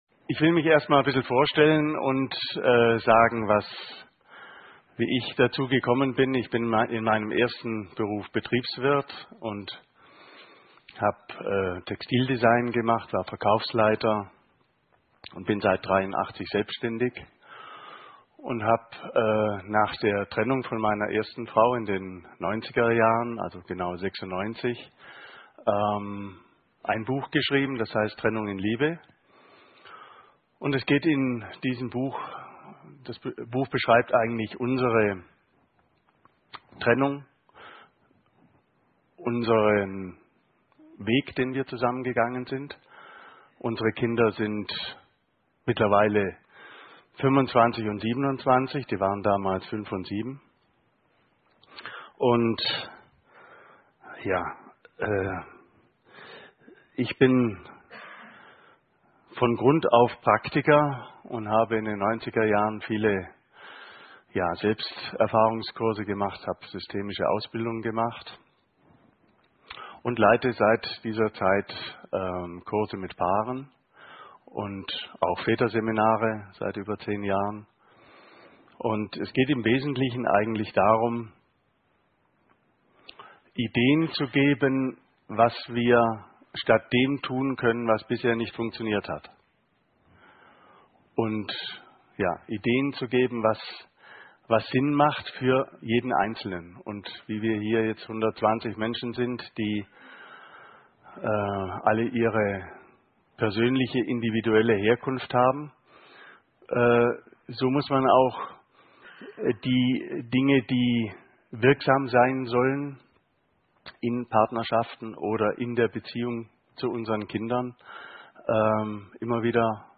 Vortrag